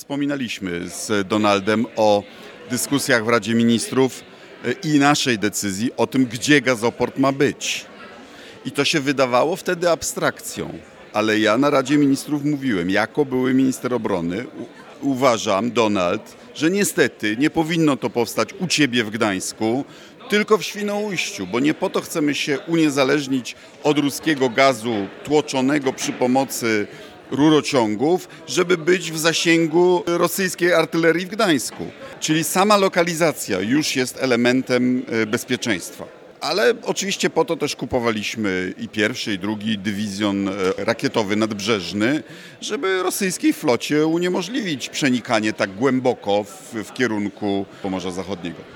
O wyborze lokalizacji dla Gazoportu w Świnoujściu wypowiedział się były Minister Obrony Narodowej Radosław Sikorski.